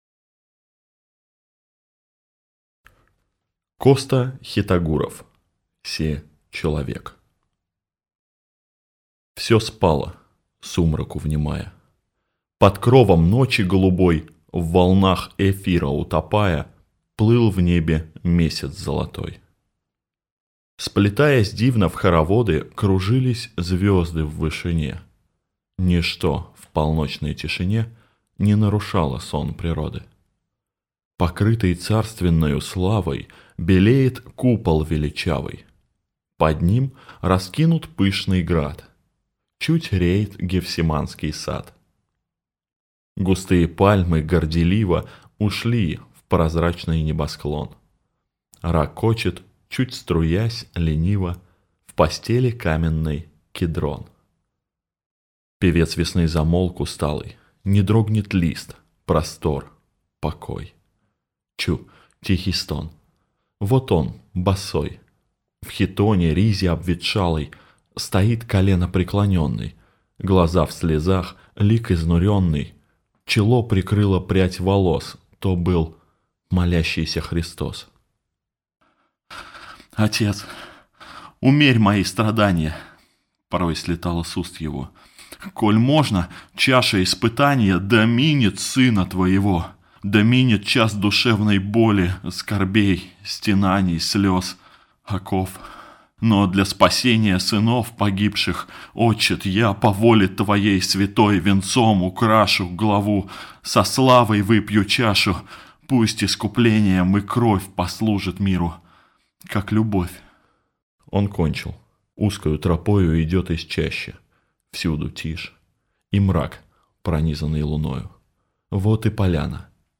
Аудиокнига Се человек | Библиотека аудиокниг